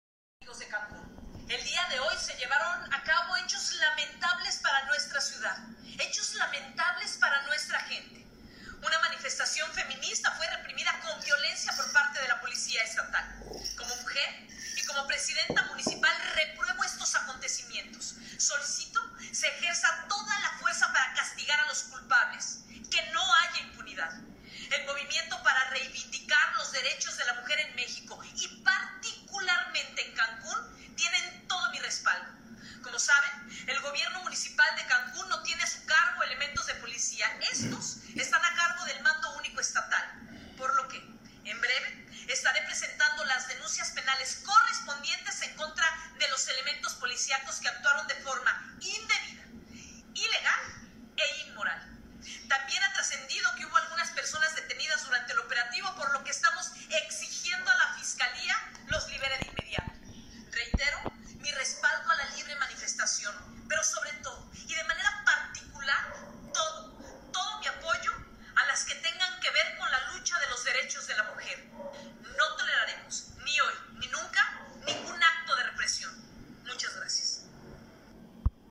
La entrevista https